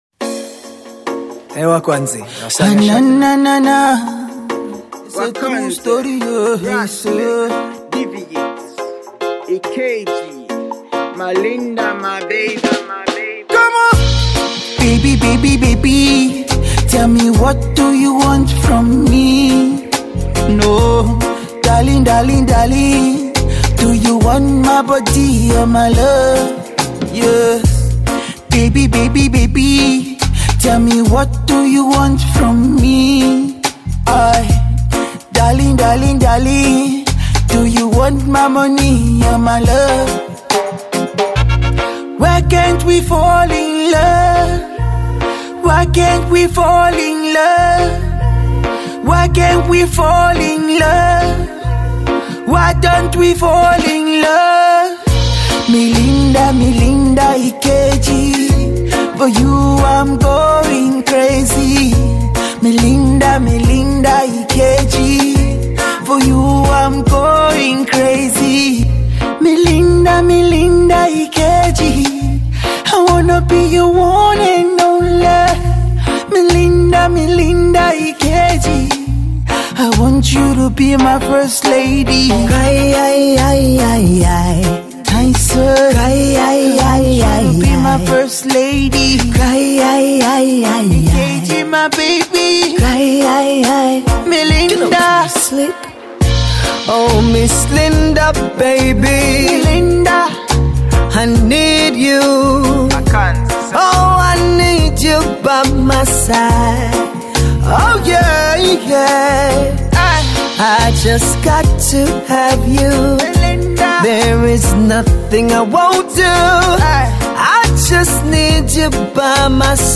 reggae love narrative